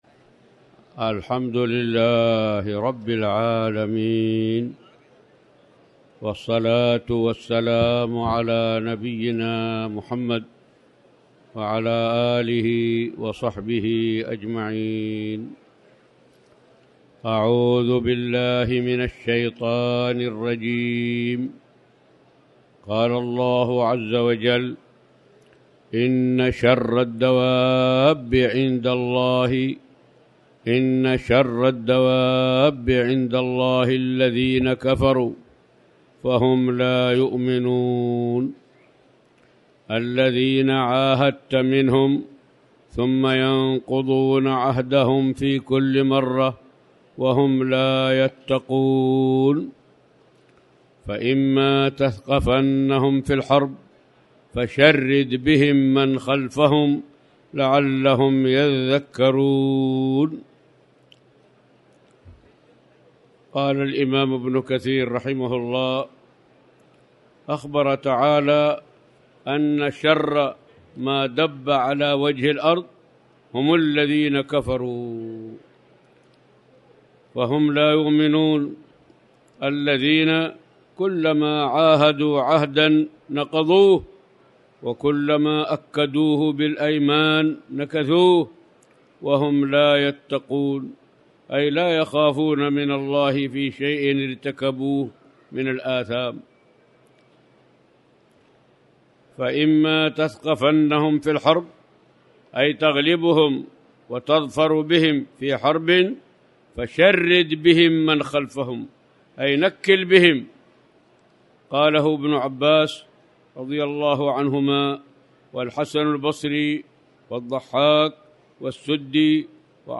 تاريخ النشر ٢ رمضان ١٤٣٩ هـ المكان: المسجد الحرام الشيخ